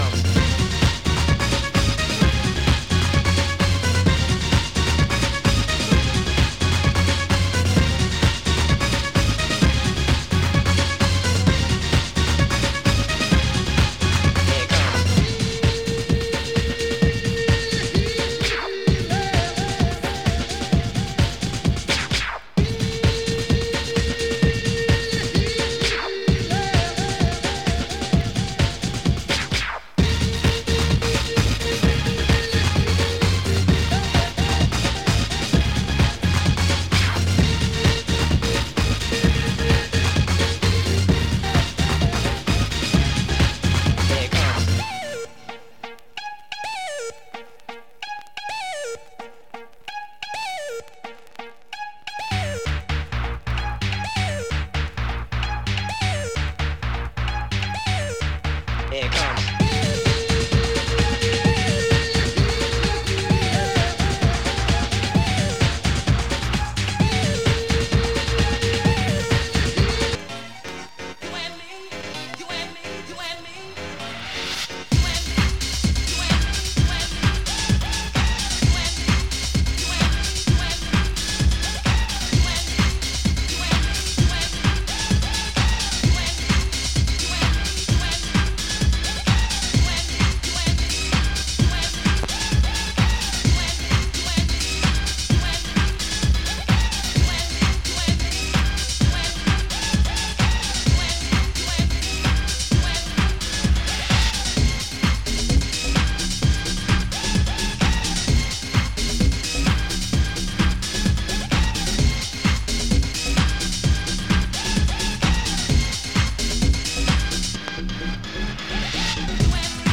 Category: Hardcore